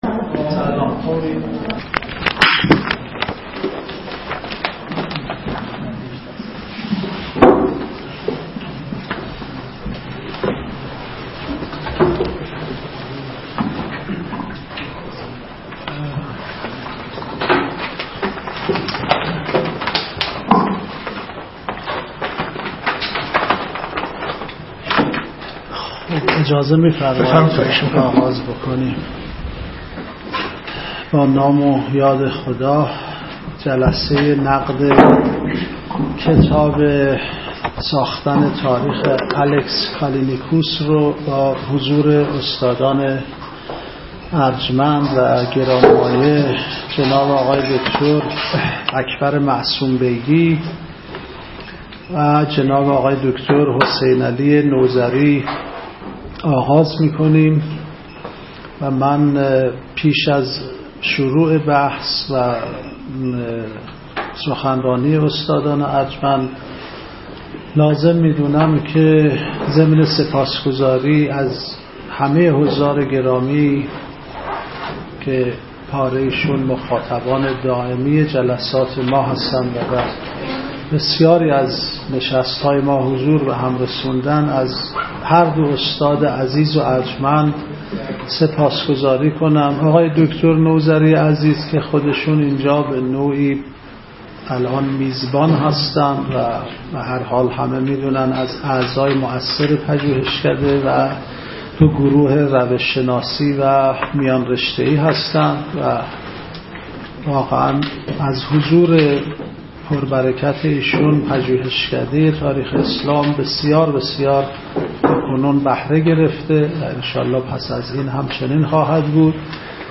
سخنرانی
در نشست نقد کتاب «ساختن تاریخ»